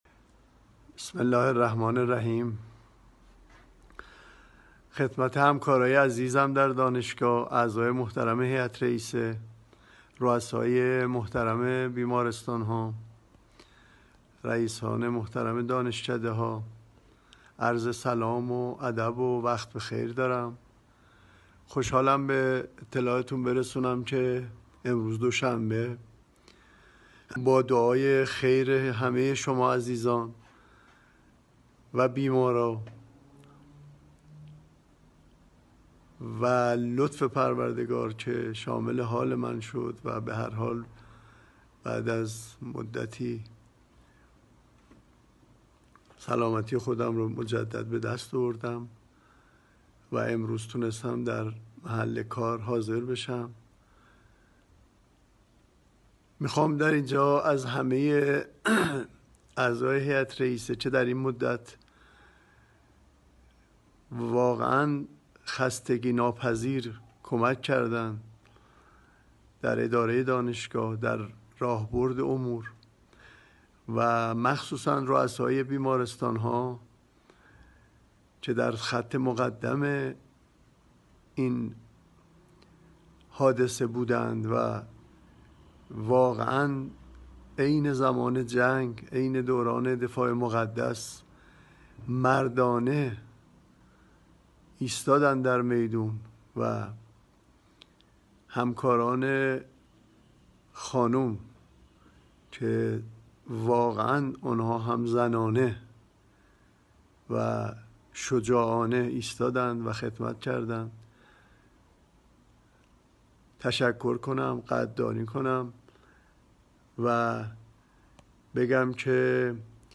پیام رئیس دانشگاه پس از بهبودی